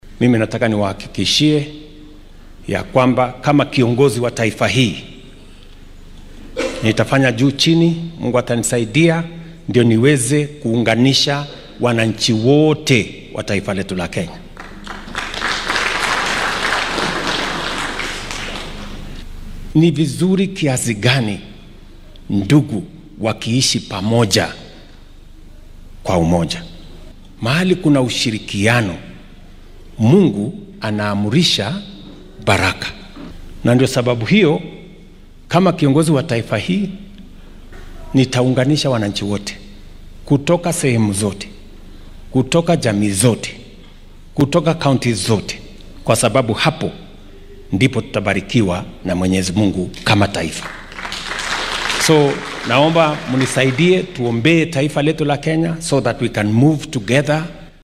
William Ruto ayaa deegaanka Limuru ka sheegay in ismaamulka Kiambu ee bartamaha dalka uu ka faaiidi doono mashaariic waawayn oo dhanka waddooyinka ah.